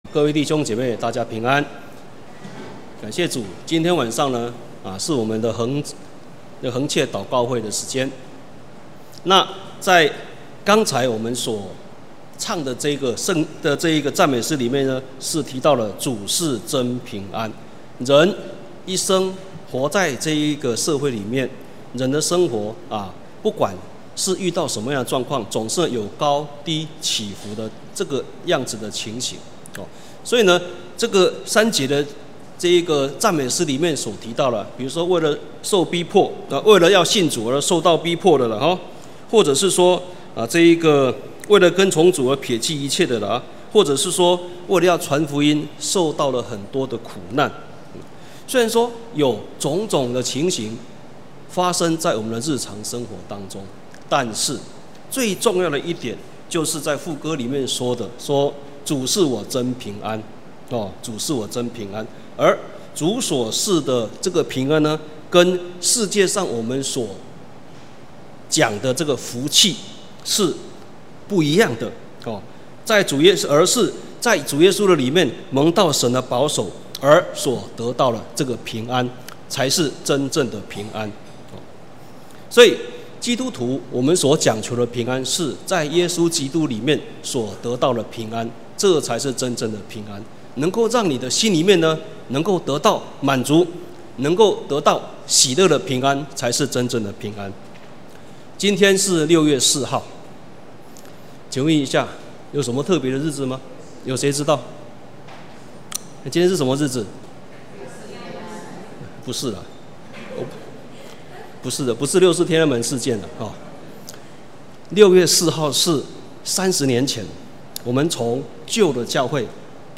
2019年6月份講道錄音已全部上線